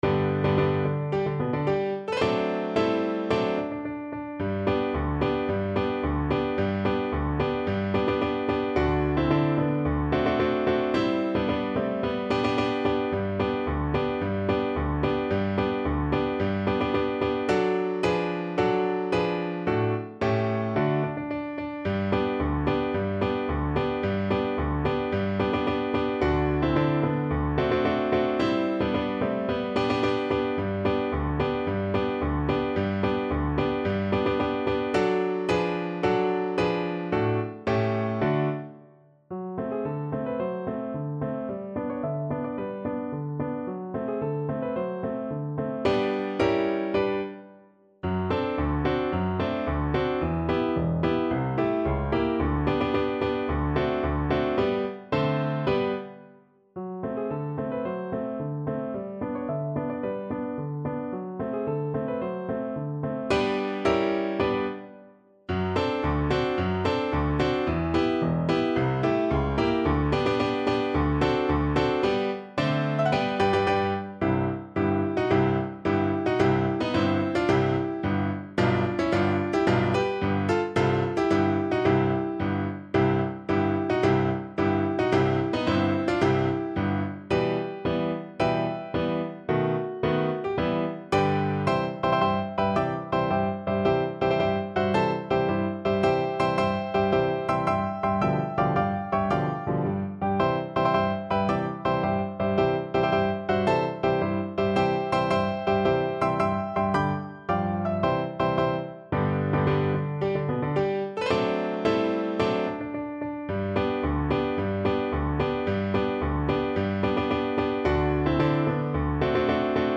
Play (or use space bar on your keyboard) Pause Music Playalong - Piano Accompaniment Playalong Band Accompaniment not yet available transpose reset tempo print settings full screen
March =c.110
G major (Sounding Pitch) (View more G major Music for Flute )
2/2 (View more 2/2 Music)
Classical (View more Classical Flute Music)